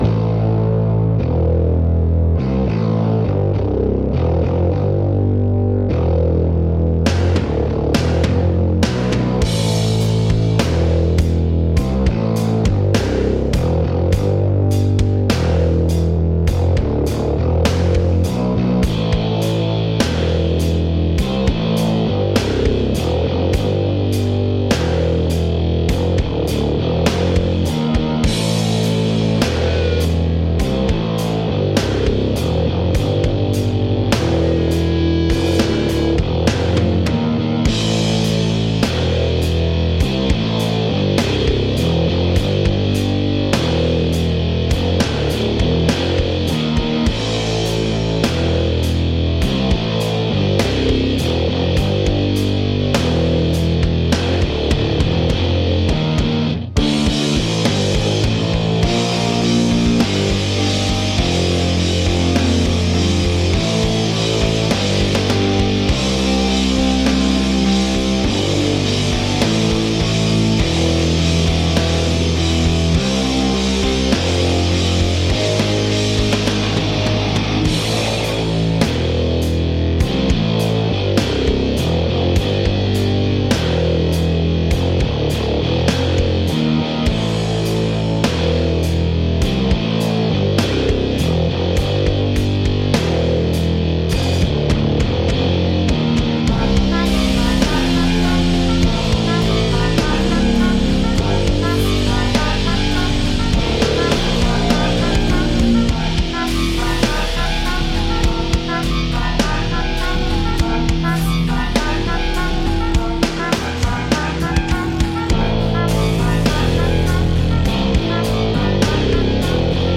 Stoner/Doom-Versuch/Writing/Sounds/Mixing
zZ 102, war länger bei 90, war mir dann aber doch zu träge.
Habe die Chords versucht, etwas kantiger zu machen, Einzeltöne und noise einzubauen.